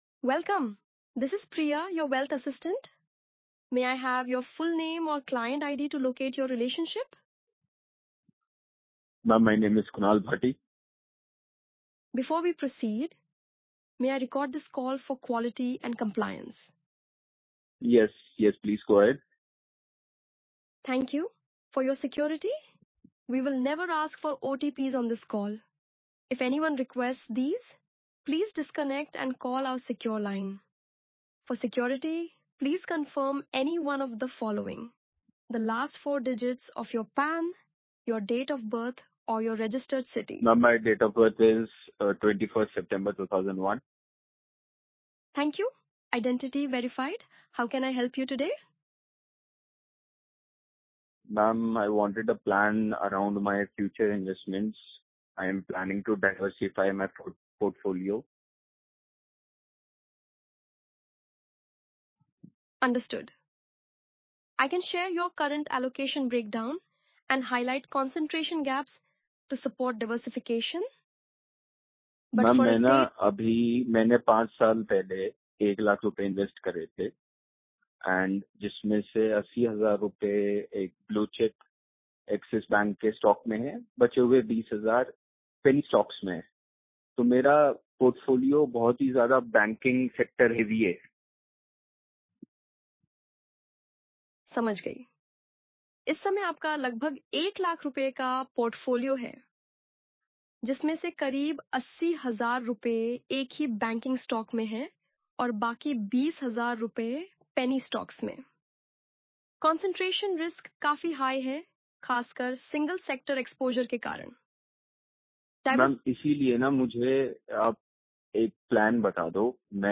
• English Indian
• Female